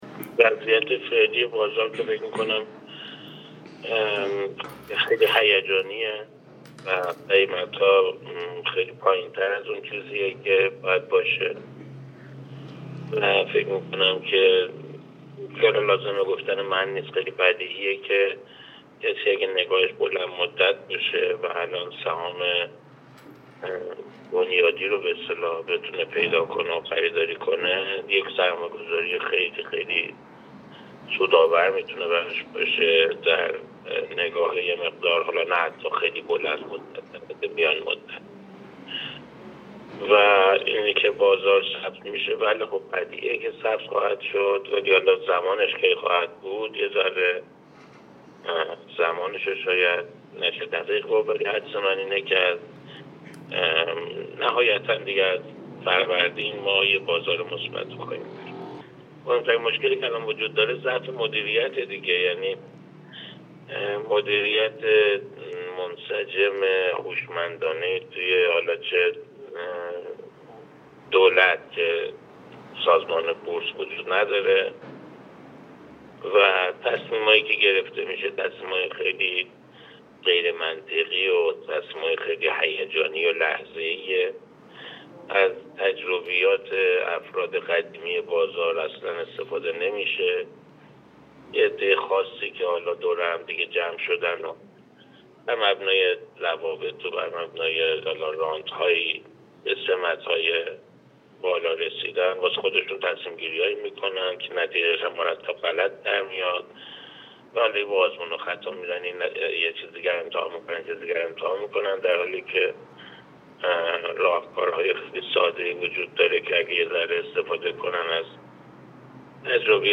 در گفت‌وگو با بورس نیوز